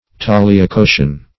Search Result for " taliacotian" : The Collaborative International Dictionary of English v.0.48: Taliacotian \Tal"ia*co`tian\, a. See Tagliacotian . [1913 Webster] The Collaborative International Dictionary of English v.0.48: Tagliacotain \Tagl`ia*co"tain\, a. (Surg.) Of or pertaining to Tagliacozzi, a Venetian surgeon; as, the Tagliacotian operation, a method of rhinoplasty described by him.